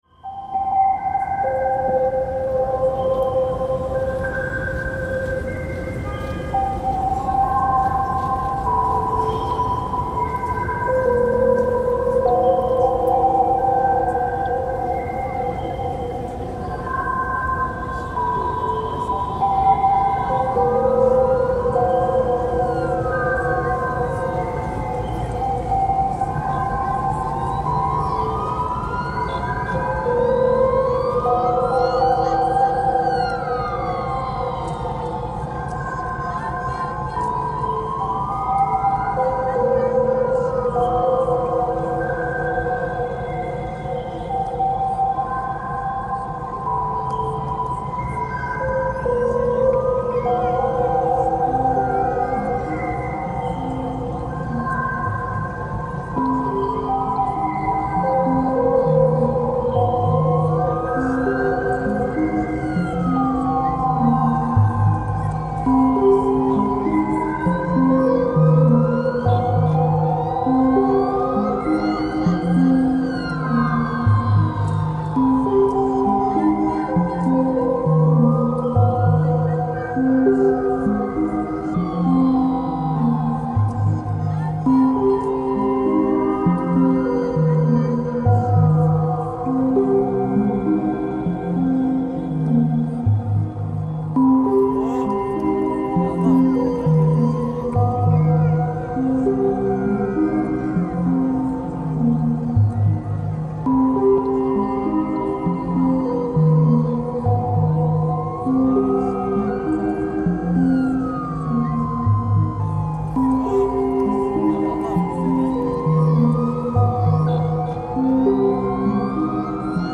Taksim Square soundscape reimagined